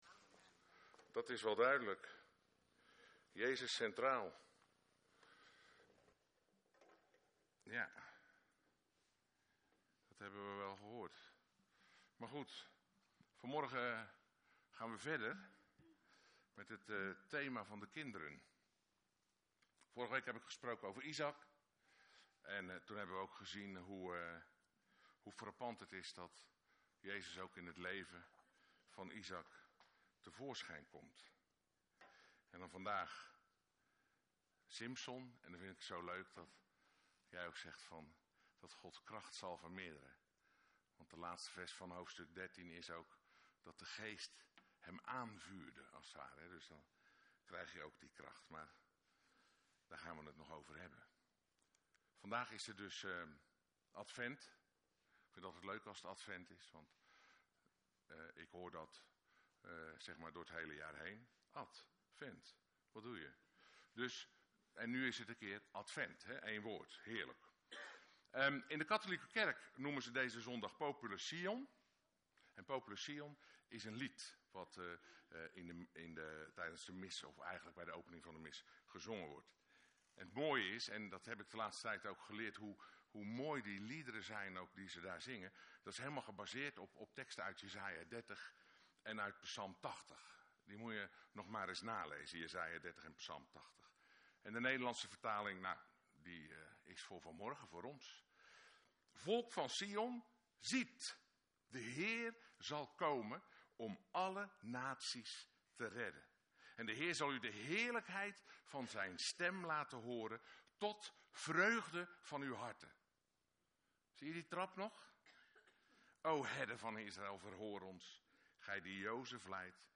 Preken Luisteren